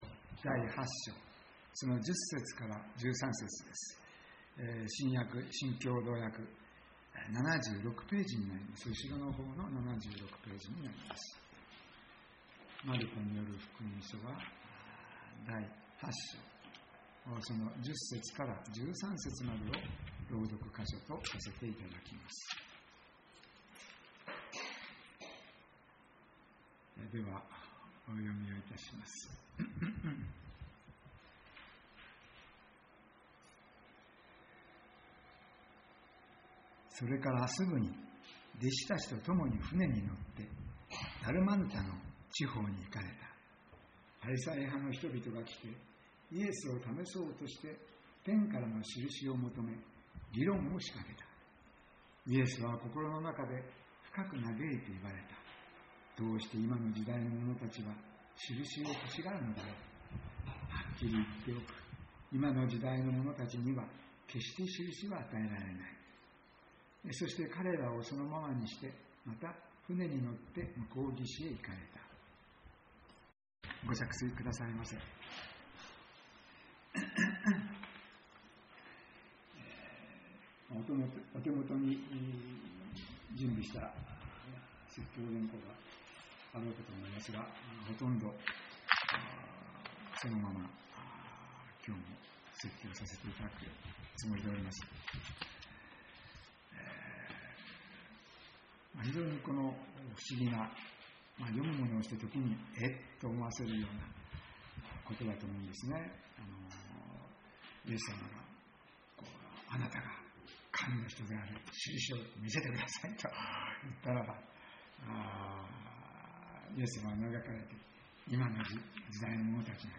栃木県鹿沼市のプロテスタント教会。